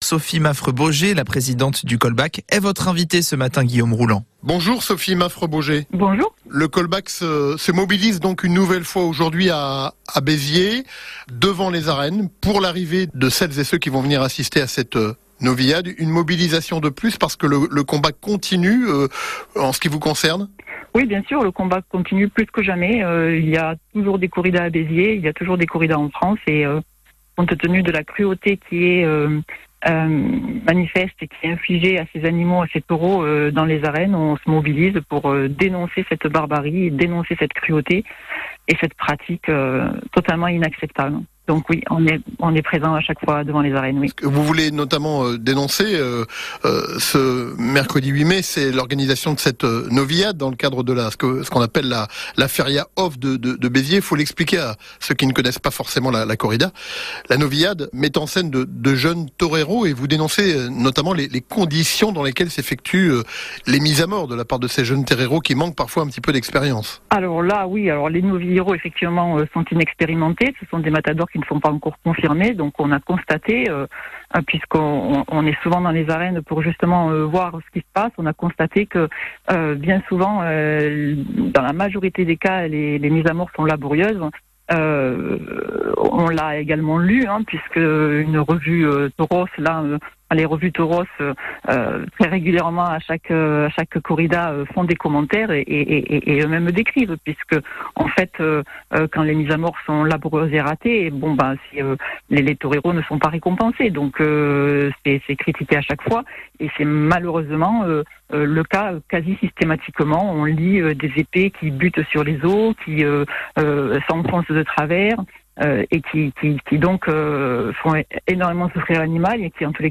L_invite-du-78-Segment-1.mp3